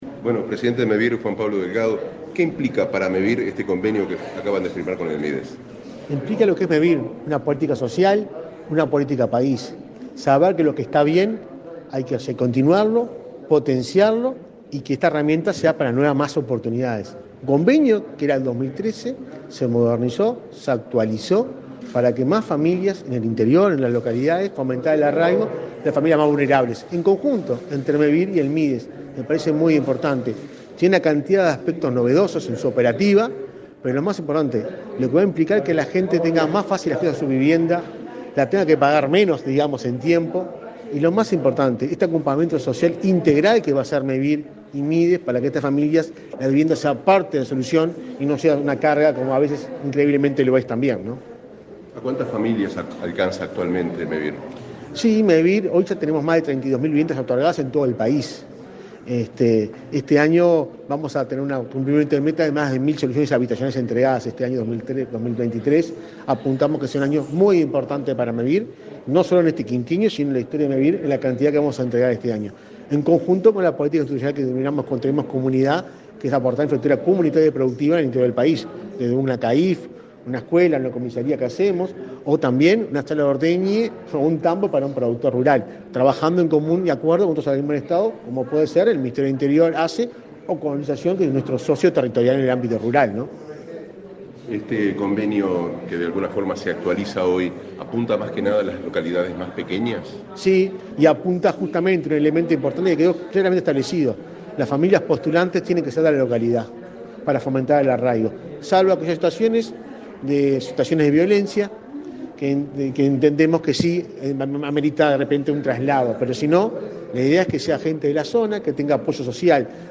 Declaraciones a la prensa del presidente de Mevir, Juan Pablo Delgado
Declaraciones a la prensa del presidente de Mevir, Juan Pablo Delgado 01/02/2023 Compartir Facebook X Copiar enlace WhatsApp LinkedIn Este 1 de febrero, el Ministerio de Desarrollo Social (Mides) y Mevir firmaron un convenio para beneficiar con viviendas a participantes de los programas del Mides. Tras el evento, el presidente de Mevir realizó declaraciones a la prensa.